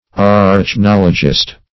Search Result for " arachnologist" : The Collaborative International Dictionary of English v.0.48: Arachnologist \Ar`ach*nol"o*gist\, n. One who is versed in, or studies, arachnology.
arachnologist.mp3